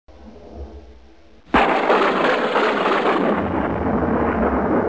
trueno
Sonido FX 38 de 42
trueno.mp3